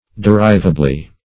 derivably - definition of derivably - synonyms, pronunciation, spelling from Free Dictionary Search Result for " derivably" : The Collaborative International Dictionary of English v.0.48: Derivably \De*riv"a*bly\, adv.